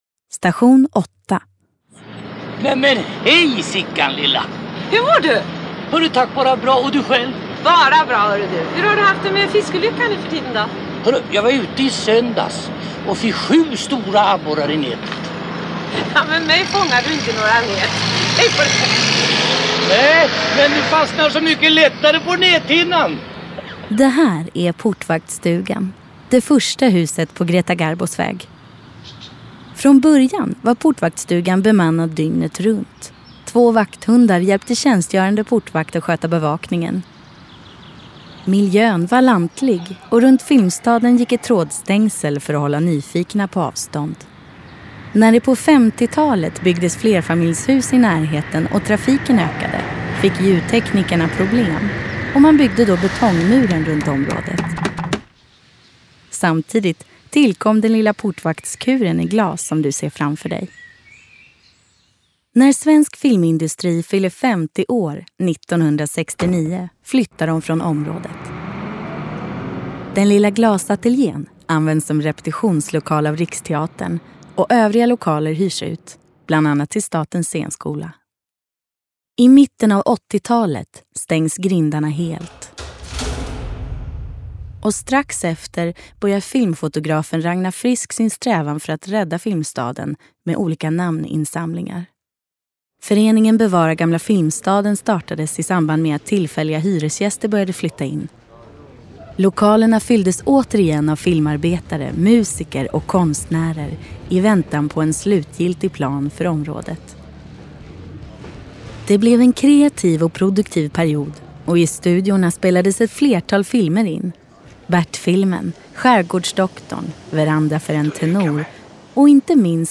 Audioguide – Filmstadens Kultur